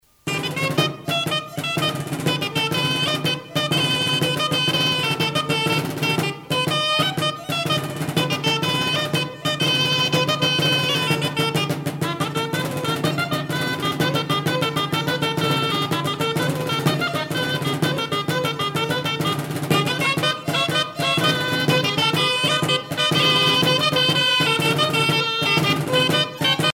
les hautbois
Pièce musicale éditée